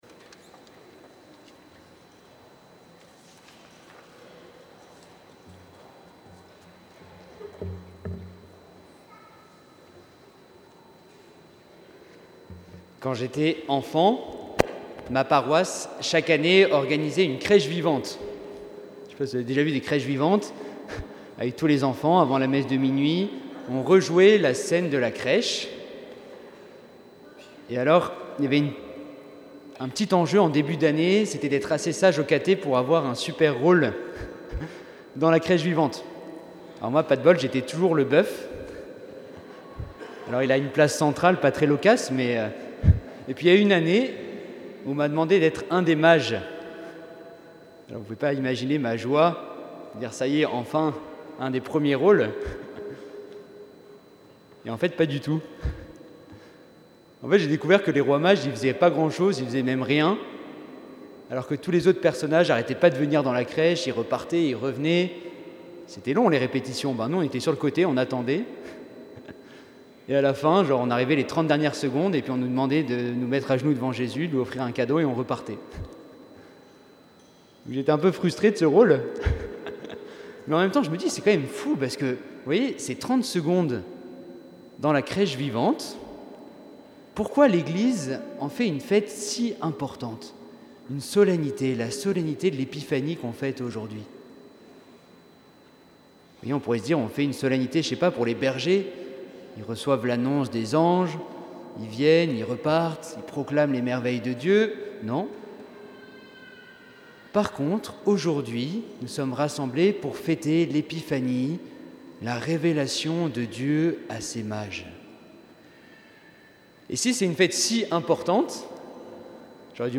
Homélie de l'Épiphanie du Seigneur - 4 janvier 2026 • Paroisse Saint-Nizier